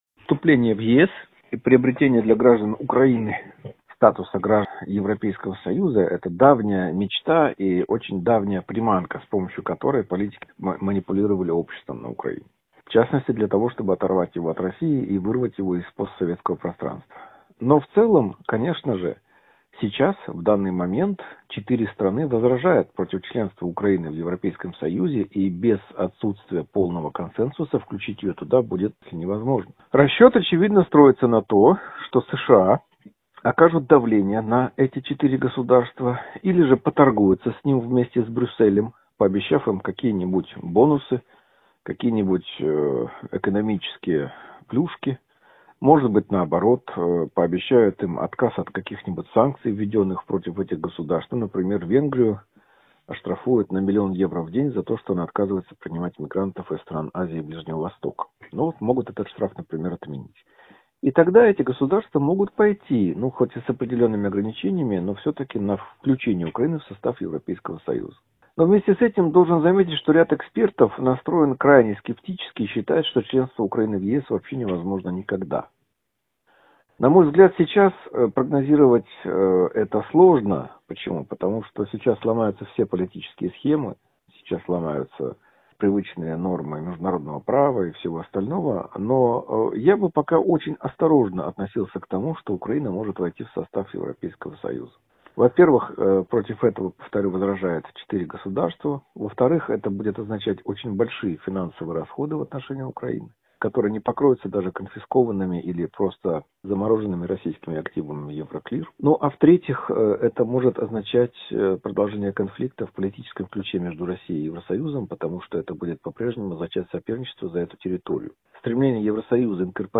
ГЛАВНАЯ > Актуальное интервью
Член президентского Совета по межнациональным отношениям Богдан Безпалько в интервью журналу «Международная жизнь» рассказал о перспективах вступления Украины в ЕС: